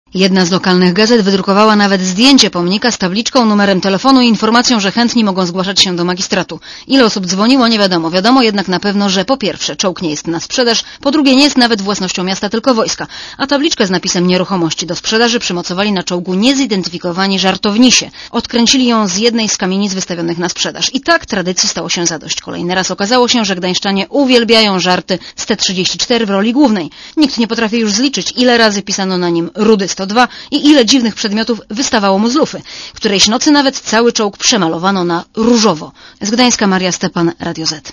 Posłuchaj relacji reporterki Radia Zet (147 KB)